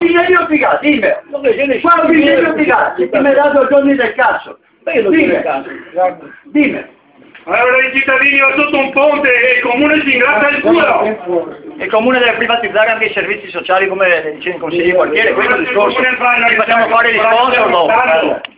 Registrazioni audio della discussione con Panchera da parte dei cittadini